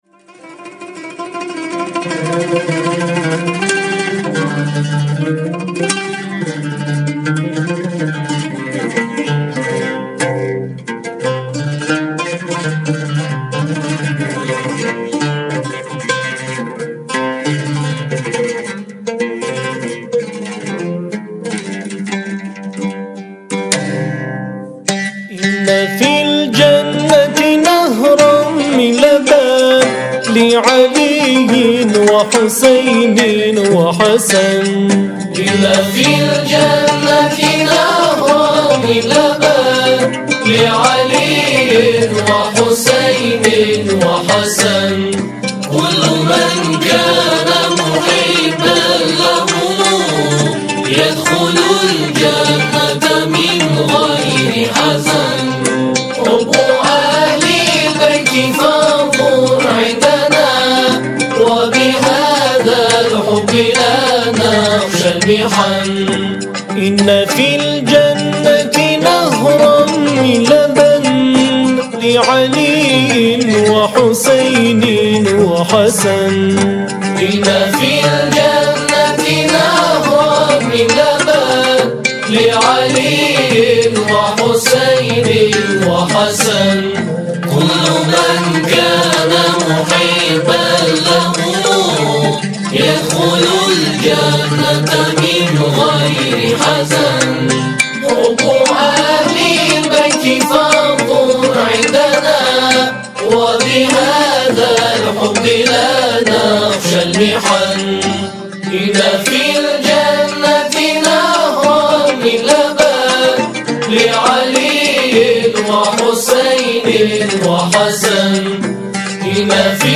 همخوانی شعری